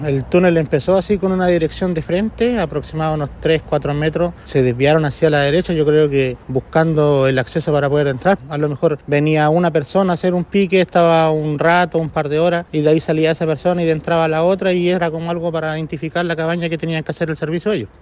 cuna-testigo-tunel-2.mp3